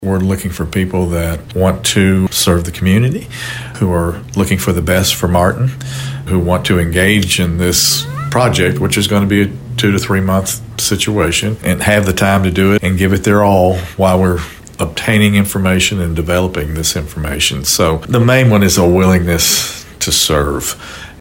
Martin Mayor David Belote tells Thunderbolt News more…